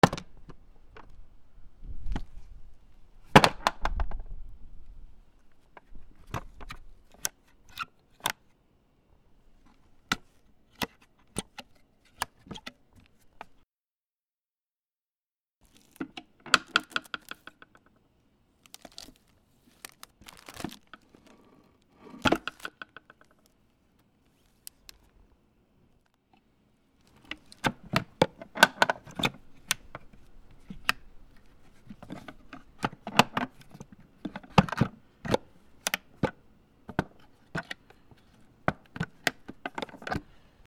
木の箱 落とす 金具をいじる フカレあり